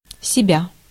Ääntäminen
Etsitylle sanalle löytyi useampi kirjoitusasu: se SE Ääntäminen France: IPA: [sə] Haettu sana löytyi näillä lähdekielillä: ranska Käännös Ääninäyte 1.